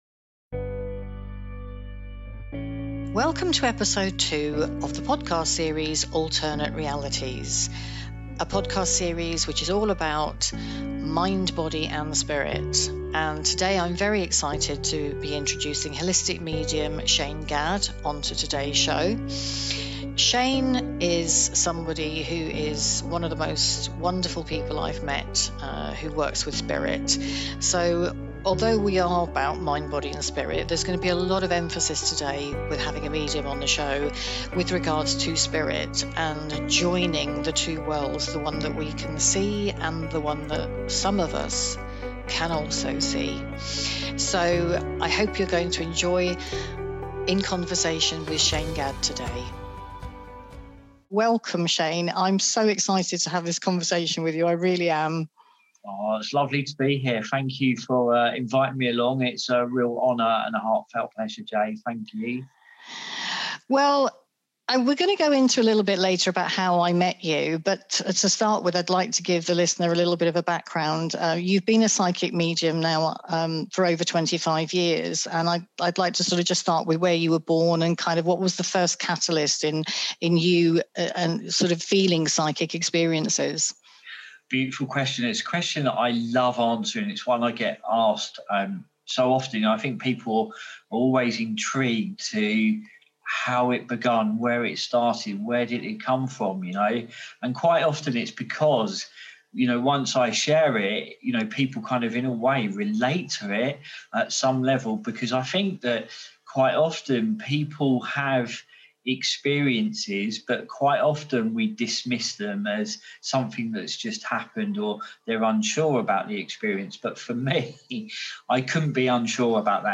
A Podcast series bringing to you conversations with ordinary people who are actually rather extraordinary with the theme that Mind, Body and Spirit are inseparable